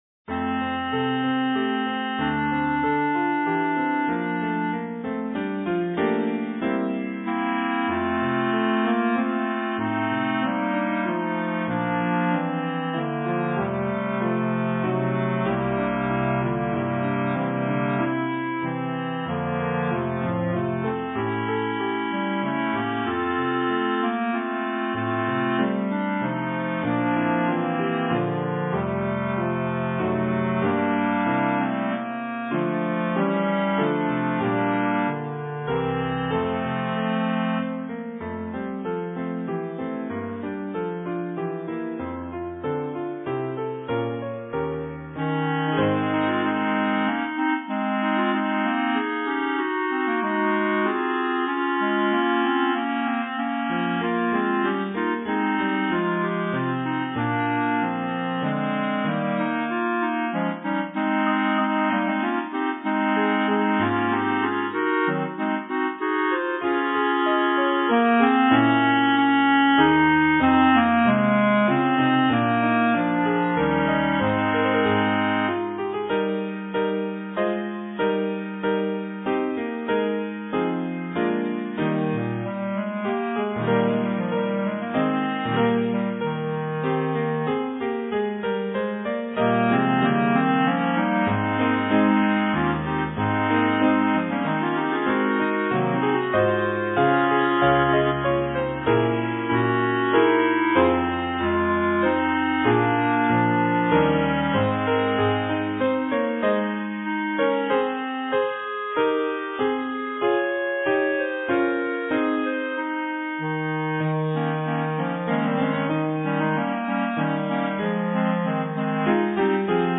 Voicing: Clarinet Trio